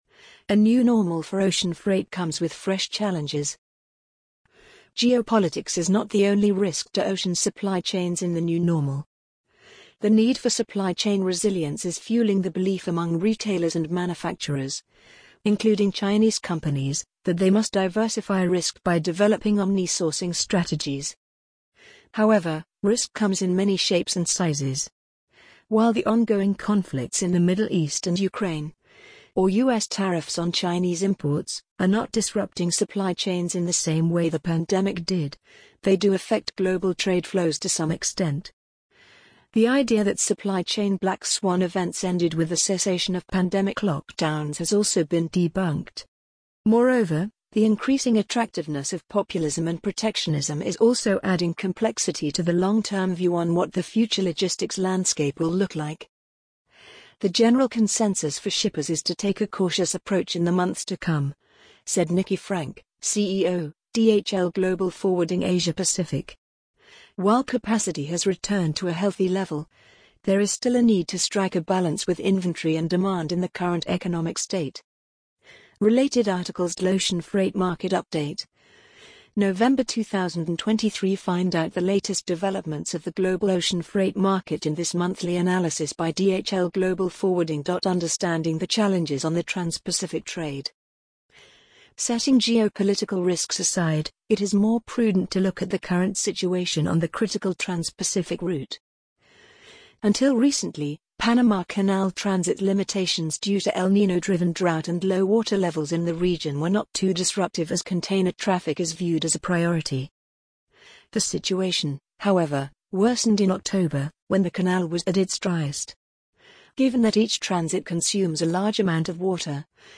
amazon_polly_47887.mp3